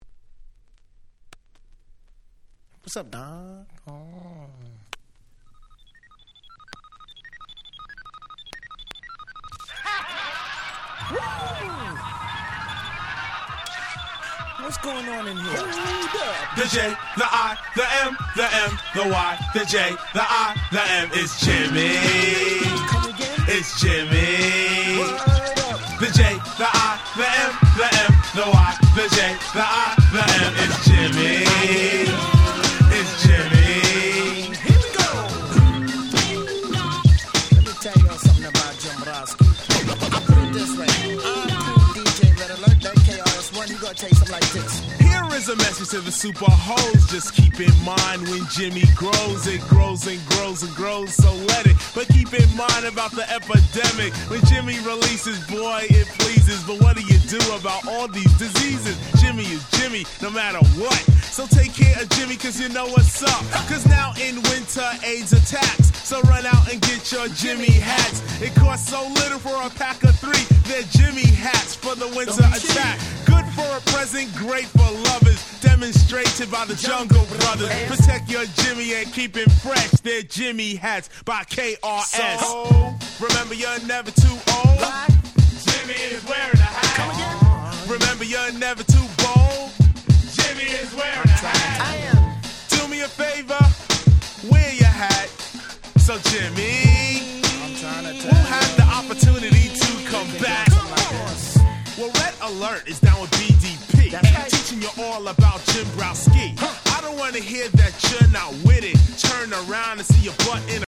88' Hip Hop Super Classics !!